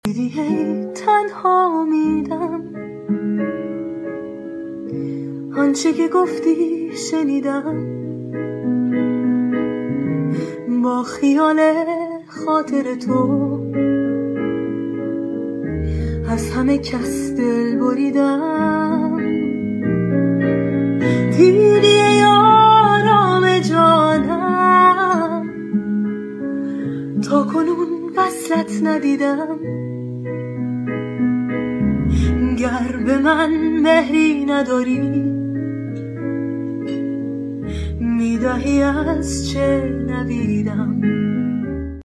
نام خواننده : دکلمه